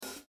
(drums)